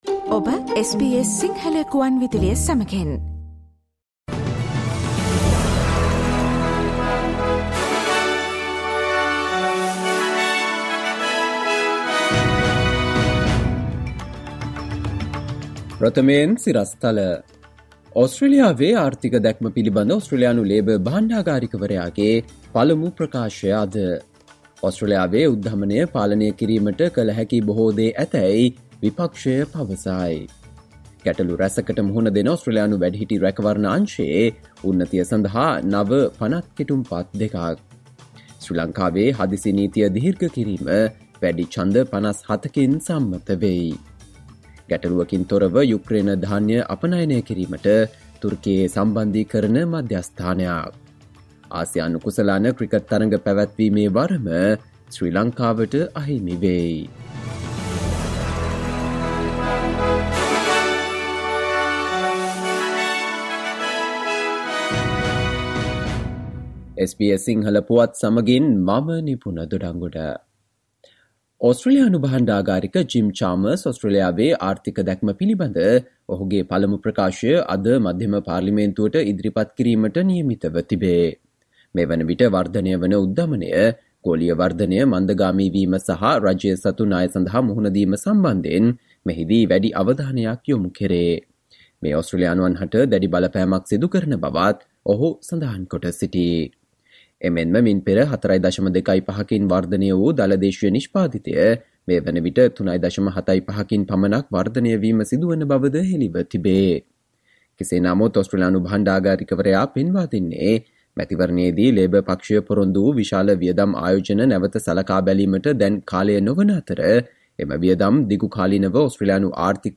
සවන්දෙන්න 2022 ජූලි 28 වන බ්‍රහස්පතින්දා SBS සිංහල ගුවන්විදුලියේ ප්‍රවෘත්ති ප්‍රකාශයට...